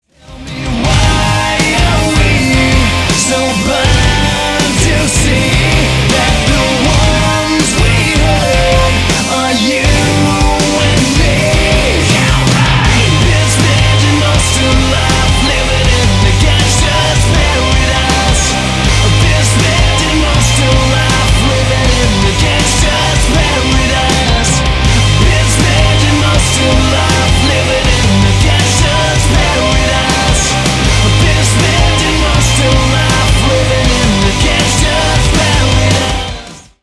Архив Рингтонов, Рок рингтоны